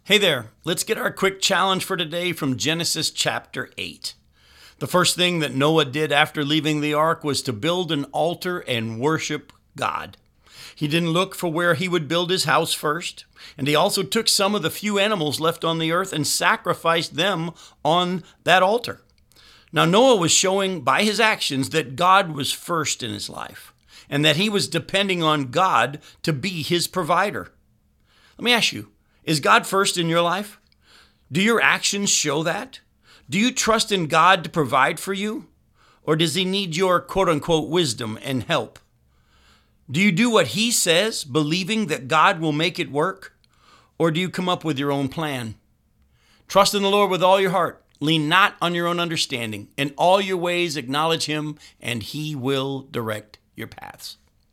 five-minute weekday radio program aired on WCIF 106.3 FM in Melbourne, Florida